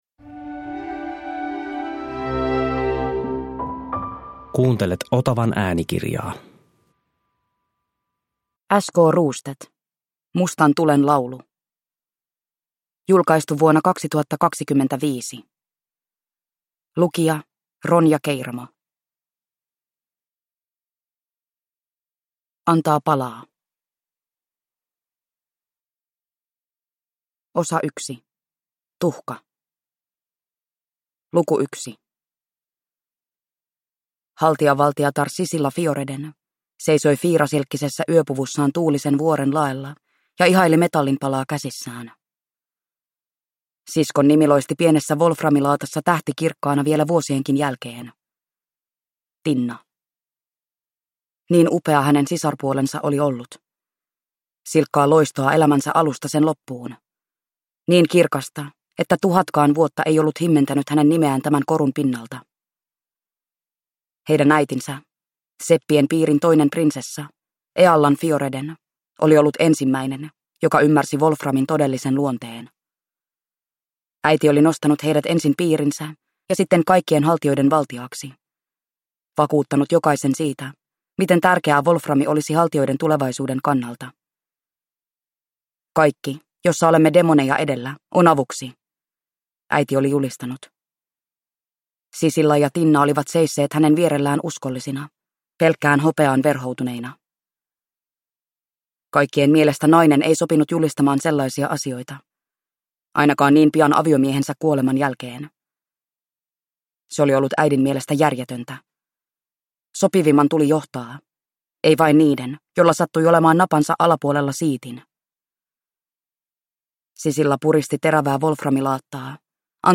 Mustan tulen laulu (ljudbok) av S. K. Rostedt